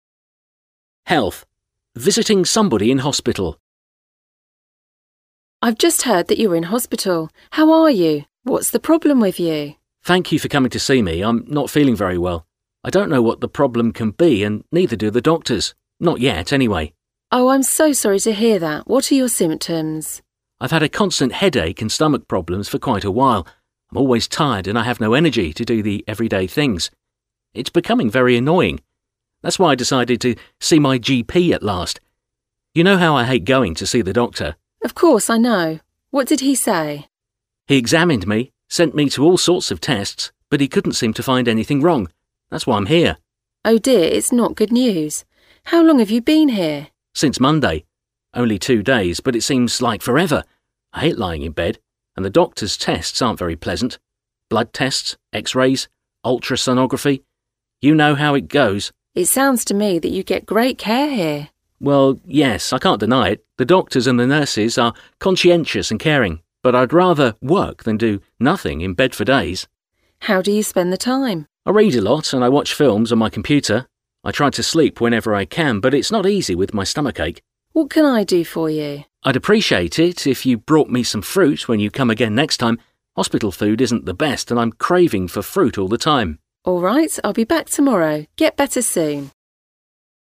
Előfordulhat, hogy szeretnél külföldön egy ismerőst meglátogatni a kórházban. Ehhez hoztam most egy hasznos-hangos párbeszédet.